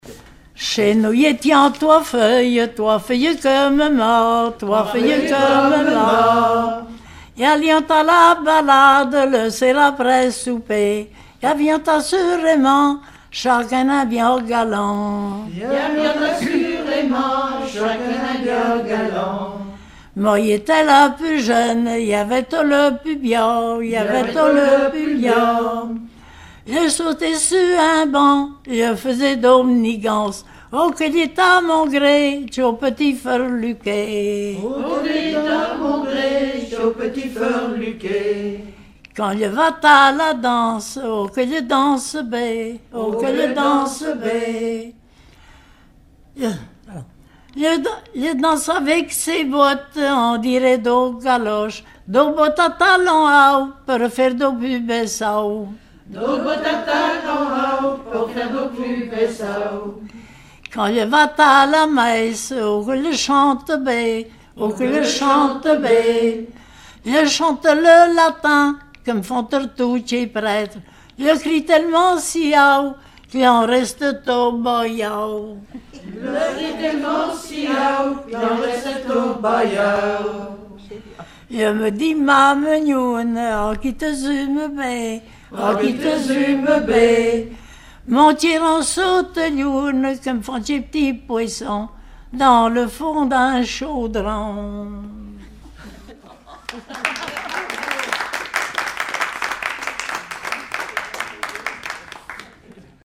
Genre strophique
Collectif-veillée (2ème prise de son)
Pièce musicale inédite